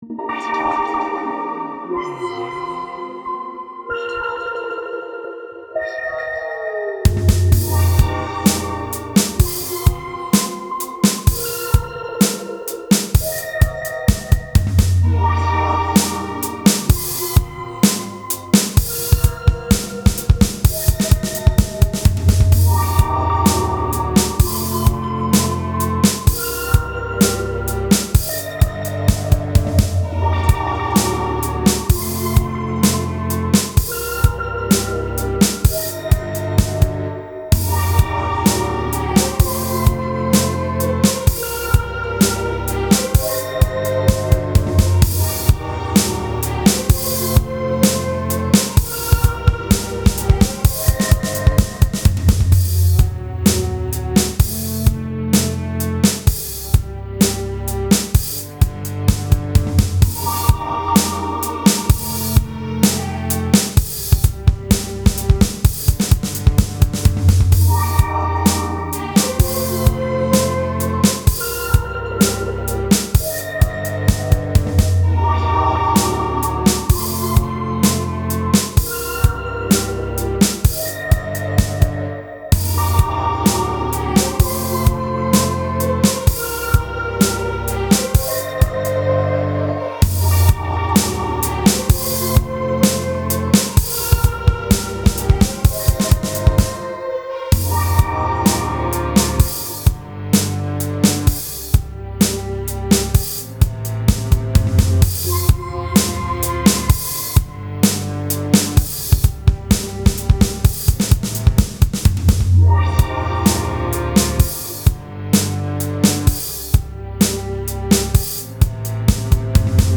It’s very moody with lots of sounds and things going on in the background.